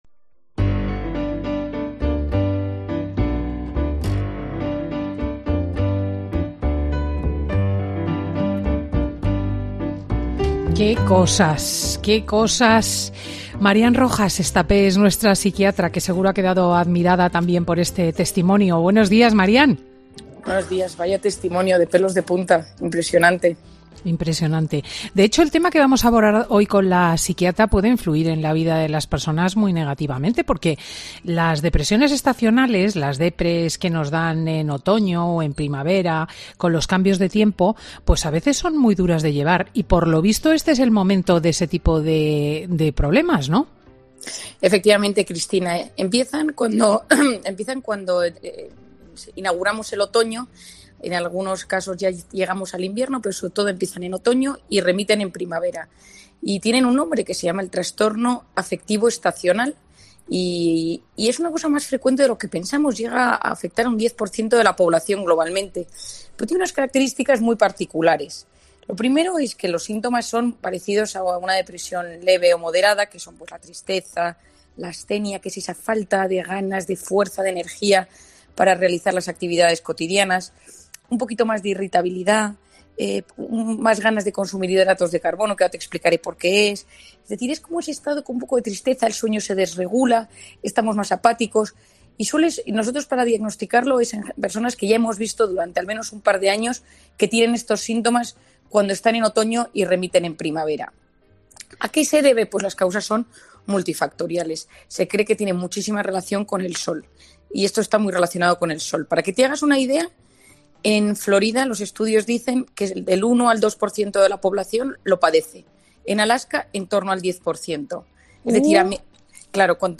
Nuestra psiquiatra comenta con Cristina en Fin de Semana los síntomas de la depresión estacional y los primeros aletazos del otoño